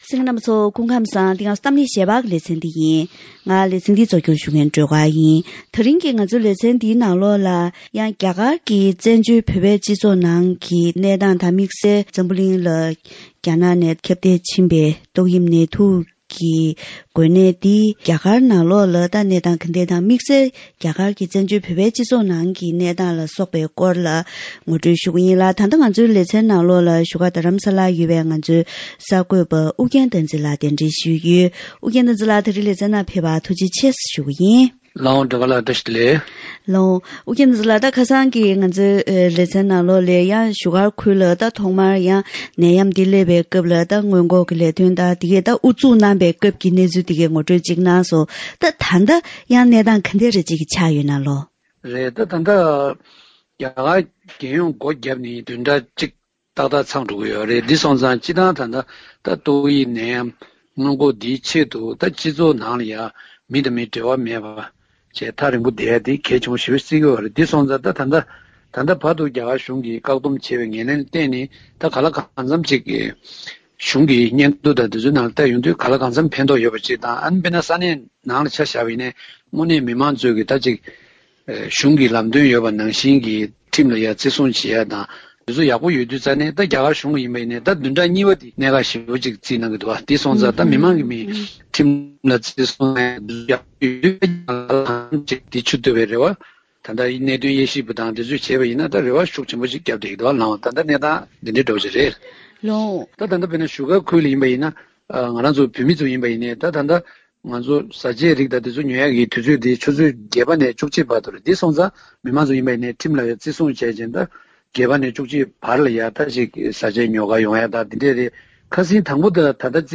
ཐེངས་འདིའི་གཏམ་གླེང་ཞལ་པར་ལེ་ཚན་ནང་ཏོག་དབྱིབས་ནད་དུག་གི་འགོས་ནད་འཛམ་གླིང་ནང་ཁྱབ་གདལ་འགྲོ་བཞིན་ཡོད་པའི་སྐབས་འདིར། རྒྱ་གར་ནང་ཡོད་པའི་བཙན་བྱོལ་བོད་མི་ཚོའི་གནས་སྟངས་དང་འགོས་ནད་ལ་སྔོན་འགོག་གི་ལས་དོན་སྤེལ་ཕྱོགས་སོགས་ཀྱི་འབྲེལ་ཡོད་སྐོར་ལ་ངོ་སྤྲོད་ཞུས་པ་ཞིག་གསན་རོགས་གནང་།།